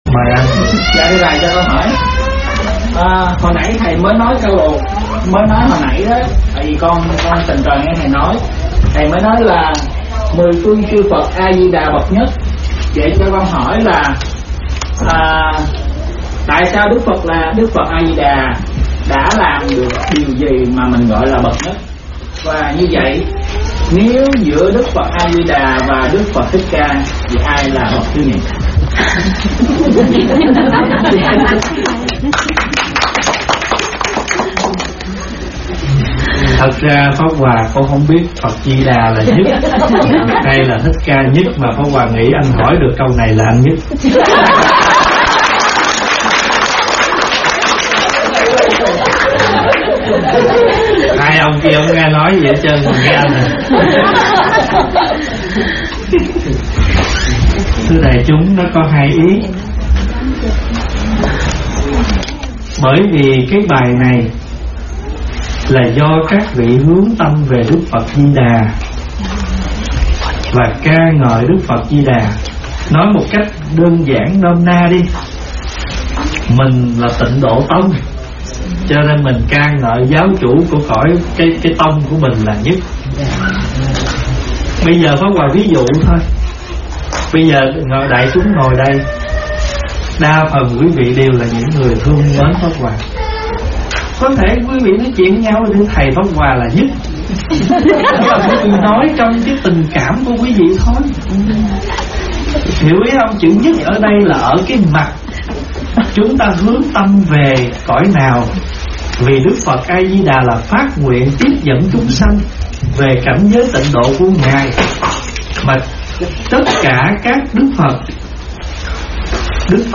Mời quý phật tử nghe mp3 vấn đáp Phải Hiểu Rõ Câu "Biết Đủ Là Vui"